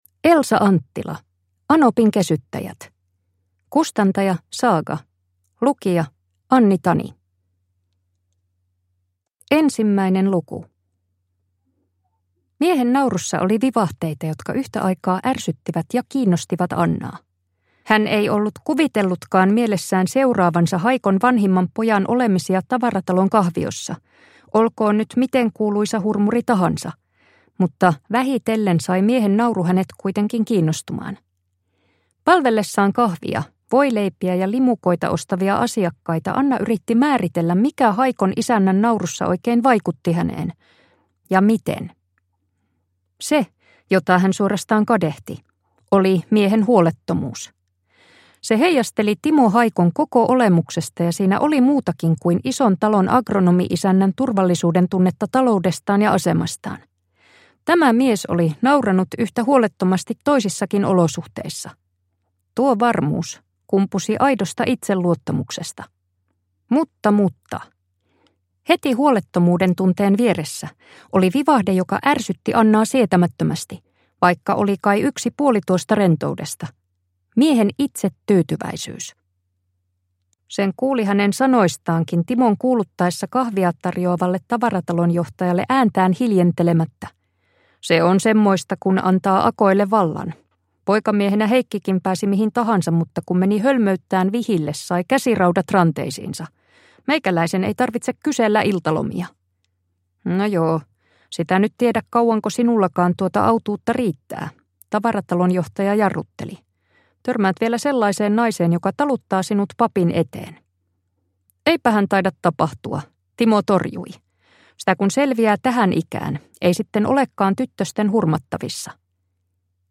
Anopinkesyttäjät (ljudbok) av Elsa Anttila